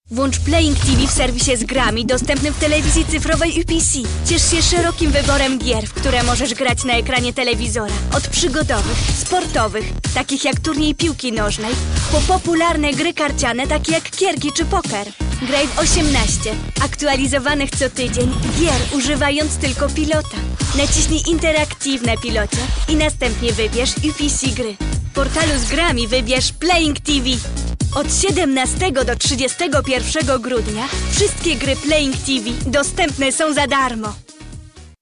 Comédienne voix-off franco-polonaise
Sprechprobe: Industrie (Muttersprache):